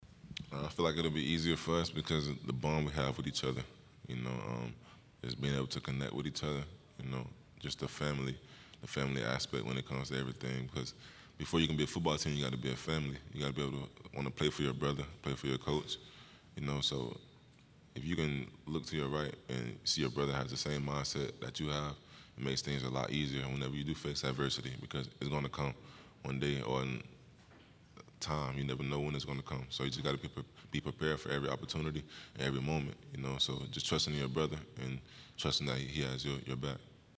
Three Florida Gators’ Football players spoke at the 2022 SEC Media day this past week.